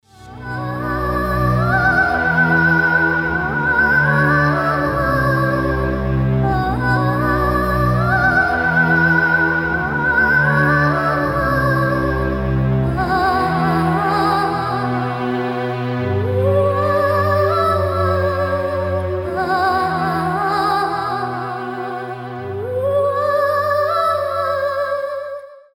• Качество: 320, Stereo
красивый женский голос